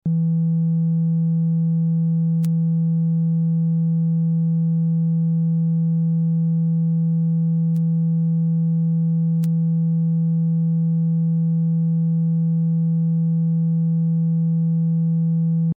Monophone Klänge:
SINUS.mp3